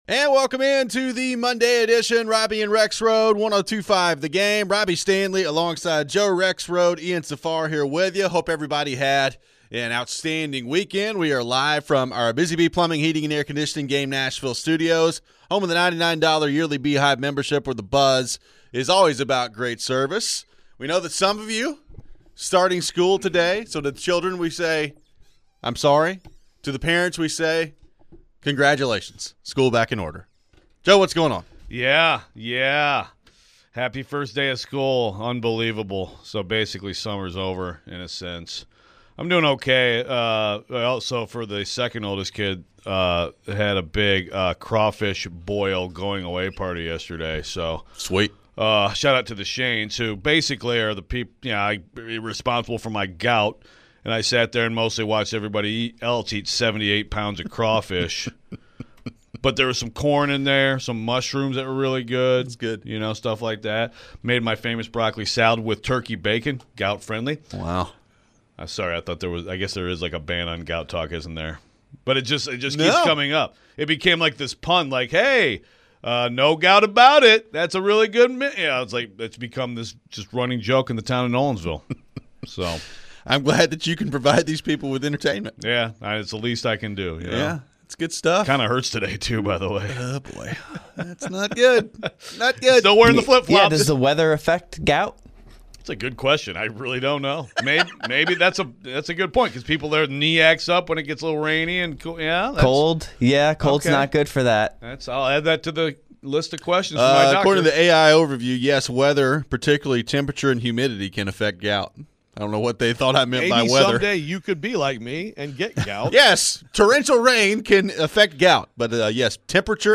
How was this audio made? Apparently HC Brian Callahan was not happy with the offense following the session on Sunday. Is there reason to worry? We head to the phones.